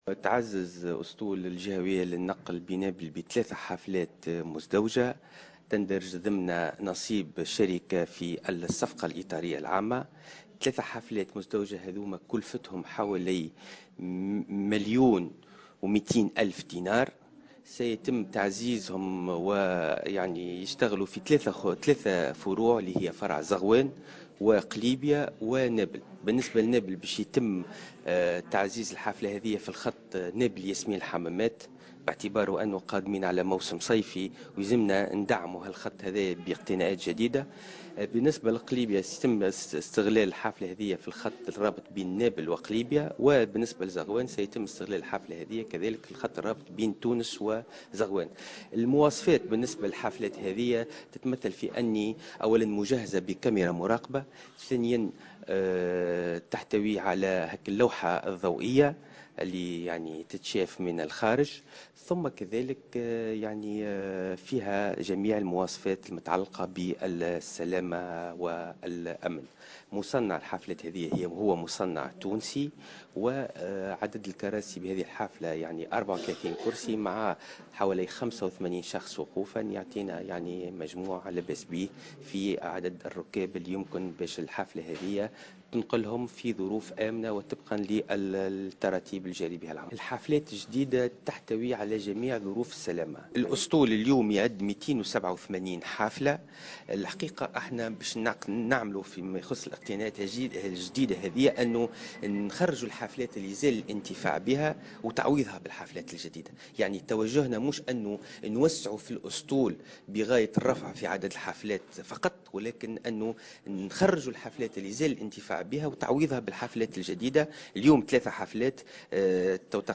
وأضاف في تصريح لمراسلة "الجوهرة أف أم" أنه سيتم استغلال هذه الحافلات (34 مقعدا في كل حافلة) بفروع زغوان ونابل وقليبة، مشيرا إلى أن المصنّع تونسي وأن هذه الحافلات مجهزة بكاميرات مراقبة ومطابقة لجميع المواصفات العالمية المتعلقة بالسلامة.